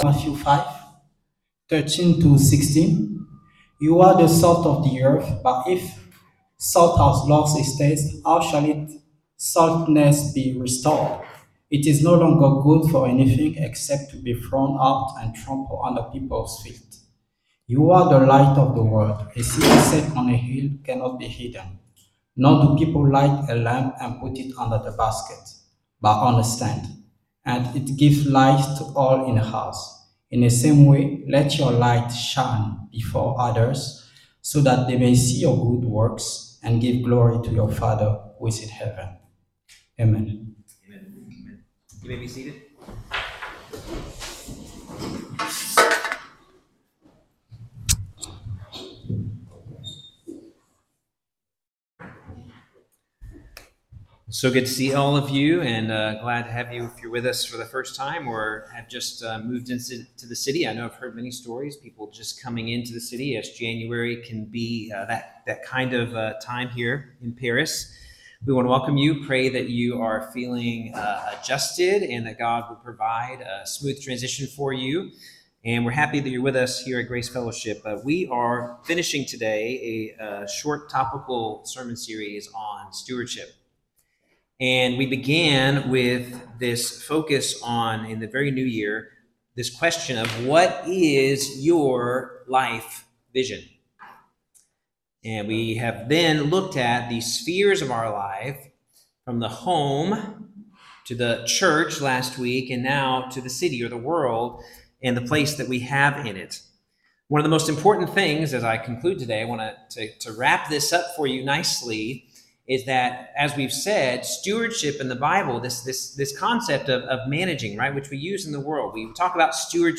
Sunday-Service-January-26.mp3